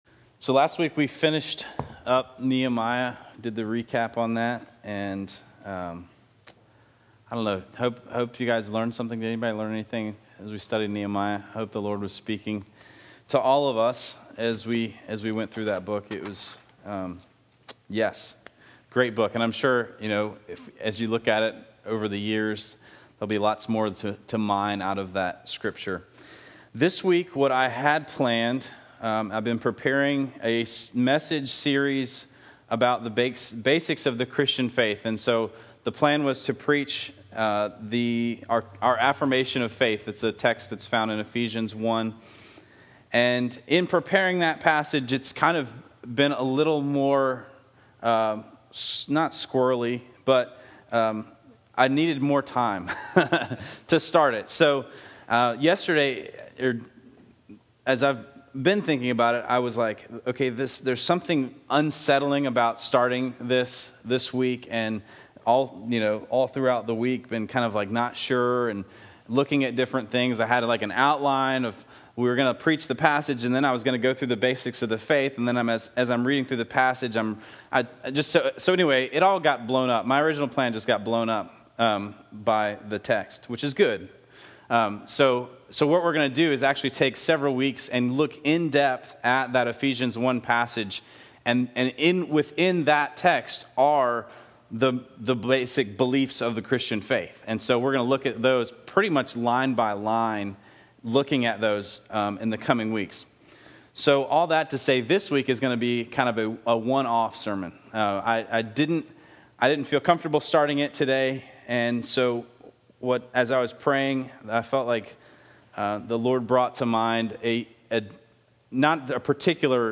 I will say that we had a glitch halfway through the message and had to switch microphones. You may notice the change in sound.)